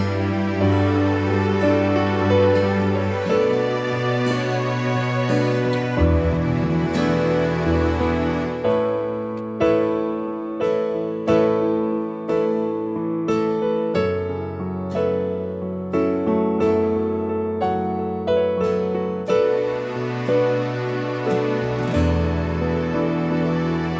No Guitar Pop (1990s) 3:41 Buy £1.50